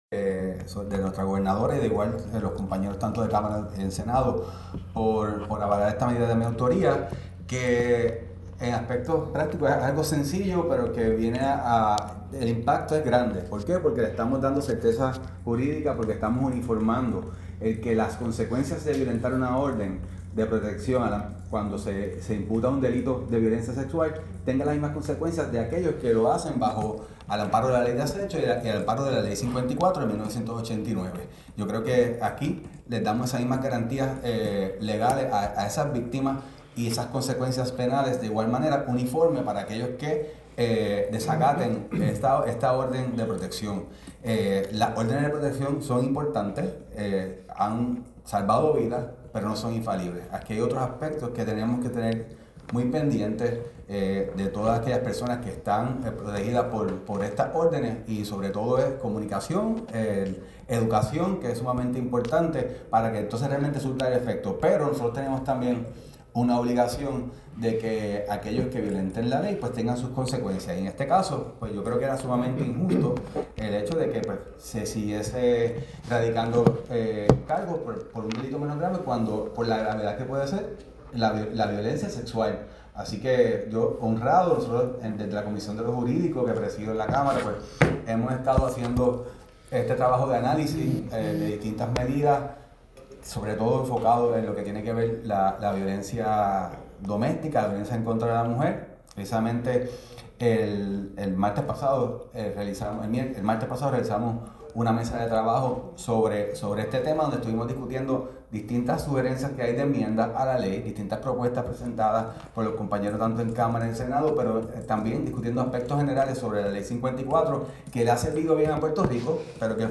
El representante, Pérez explicó en conferencia de prensa “En Récord” que esta nueva ley propone tipificar como delito grave el incumplimiento de órdenes de protección emitidas a favor de víctimas de violencia sexual, reforzando así el marco legal existente y garantizando mayores salvaguardas para quienes han sido sobrevivientes de este tipo de agresión.